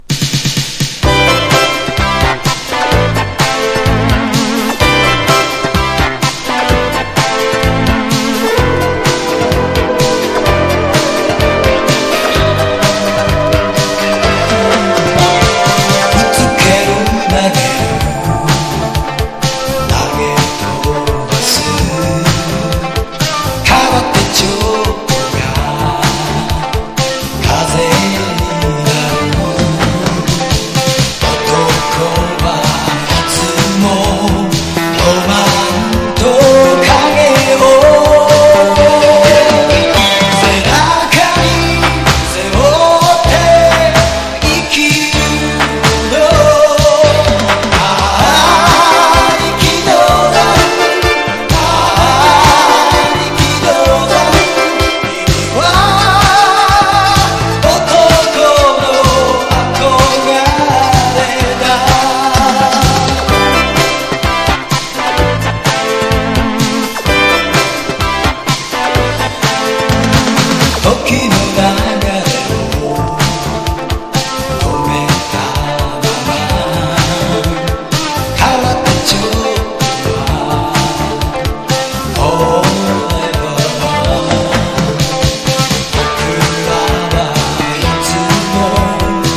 CITY POP / AOR# 和ジャズ# SOUNDTRACK / MONDO# FREE